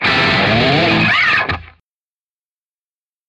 Хаотично провели рукой по струнам электрогитары